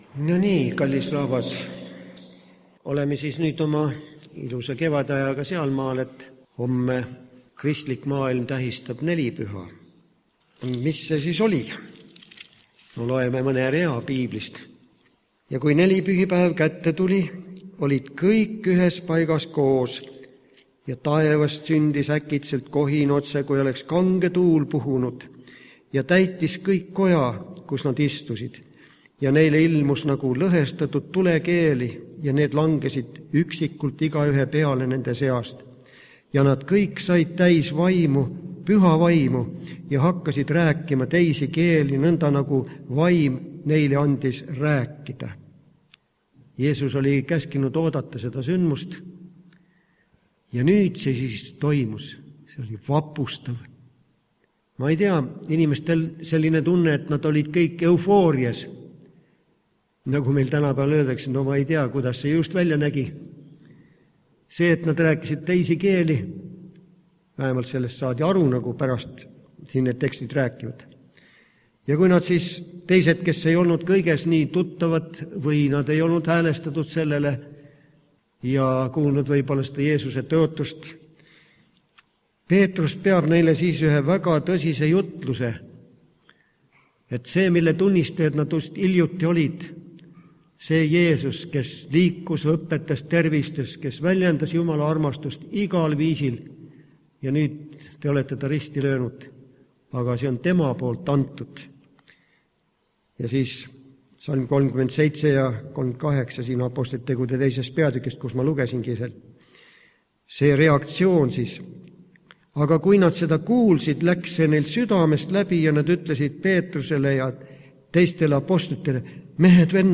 Muusikalise hetkena vana laul
Jutlused